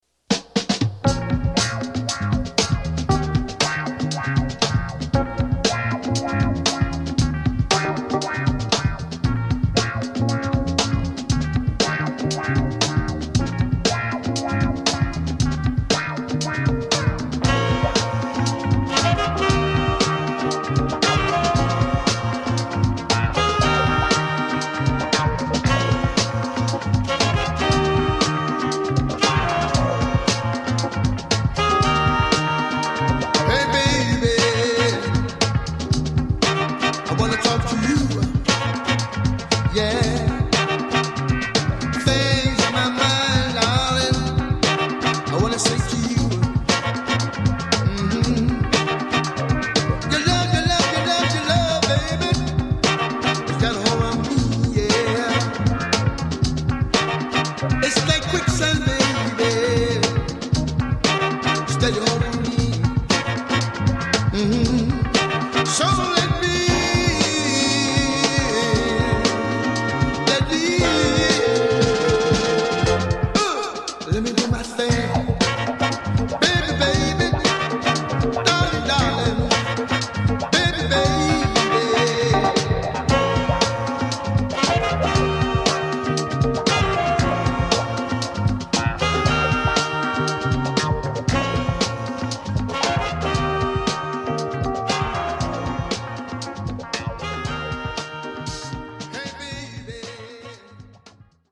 Tags: Funk , Soul , Panama
drums
clavinet
on guitar.
electric bass and keyboards